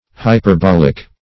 Hyperbolic \Hy`per*bol"ic\, Hyperbolical \Hy`per*bol"ic*al\, a.